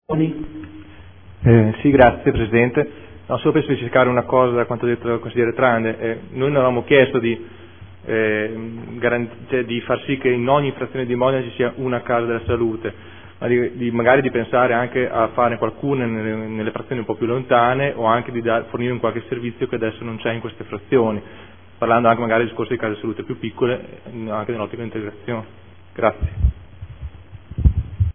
Seduta del 3/11/2014. Dibattito su ordini del giorno